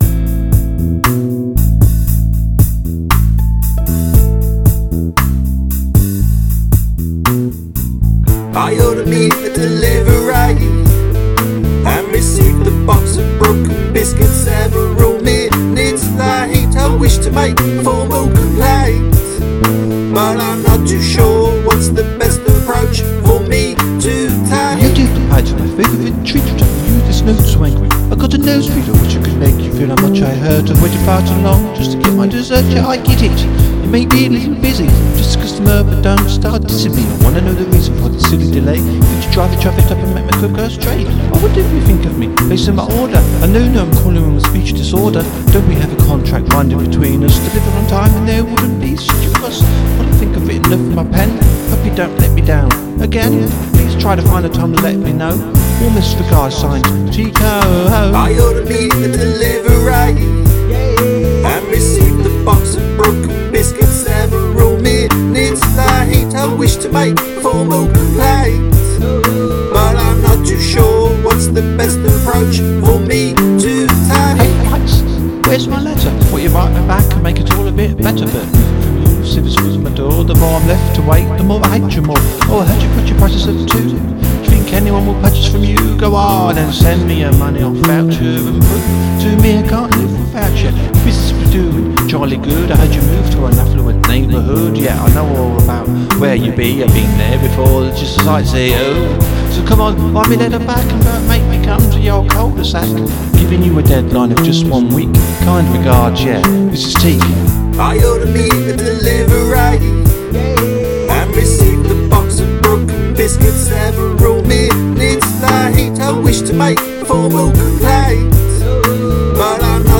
Angry voicemail (telephone message)
Jealous 'ooh' by a member of the royal family